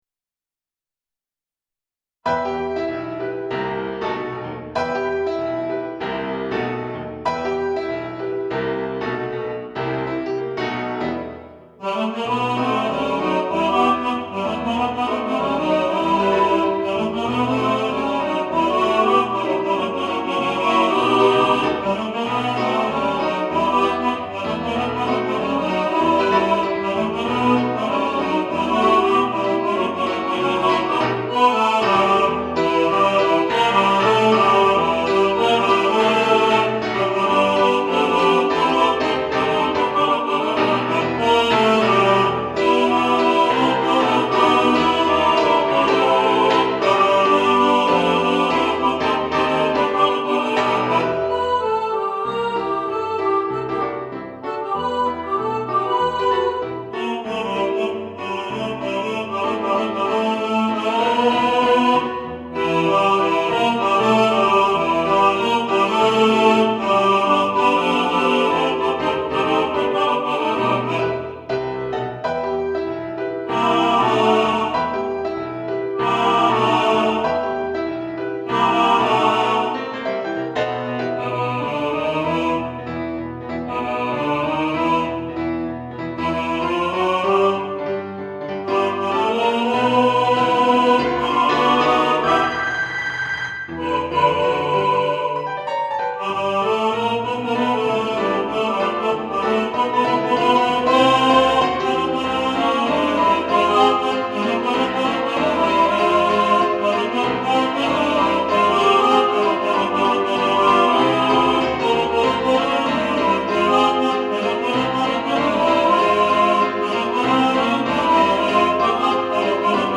Friend Like Me All Voices | Ipswich Hospital Community Choir